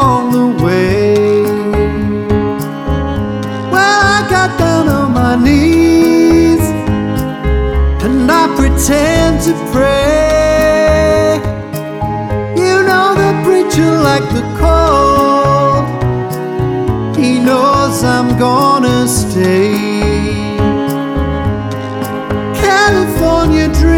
Up 2 Female Key